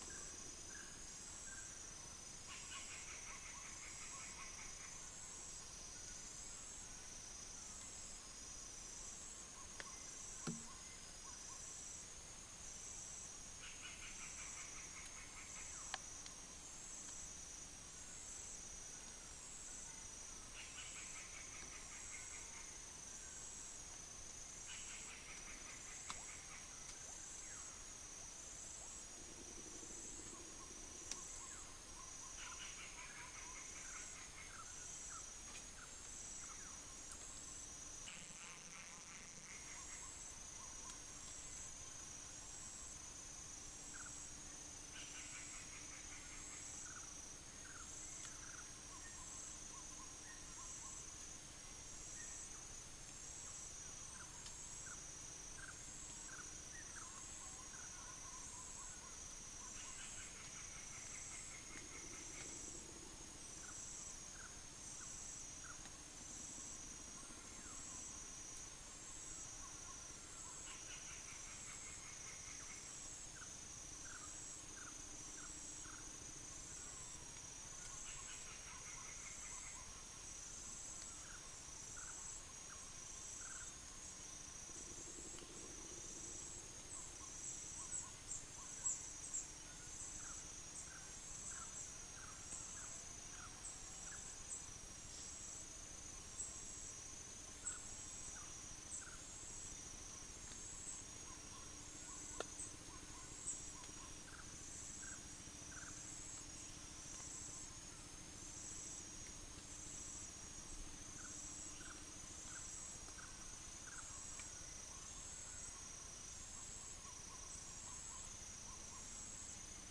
Macronus ptilosus
Dicrurus paradiseus
Caprimulgus macrurus
Gracula religiosa